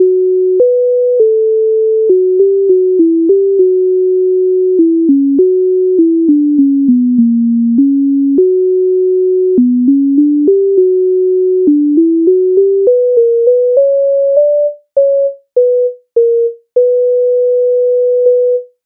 MIDI файл завантажено в тональності h-moll
Ой чого ти дубе Українська народна пісня зі збірки Михайловської
Ukrainska_narodna_pisnia_Oj_choho_ty_dube.mp3